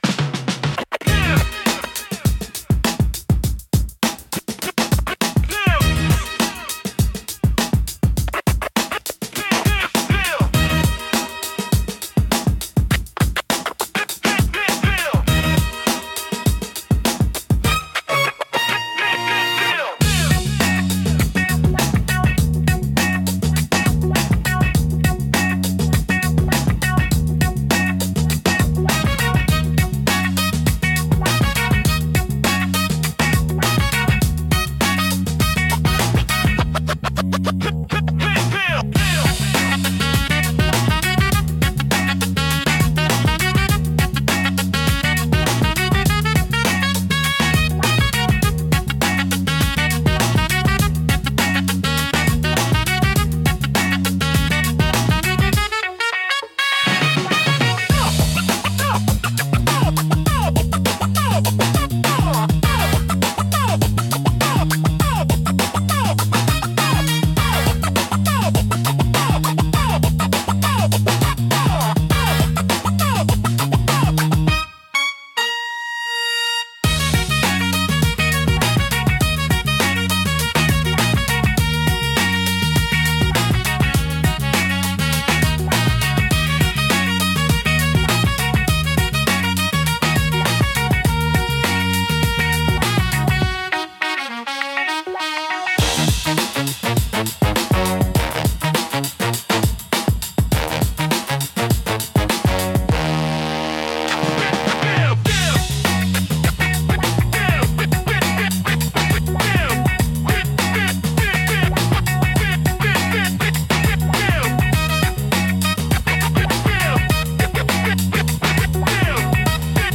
若々しく自由なエネルギーを感じさせ、都会的でポップな空間作りや動画配信の明るい雰囲気づくりに効果的です。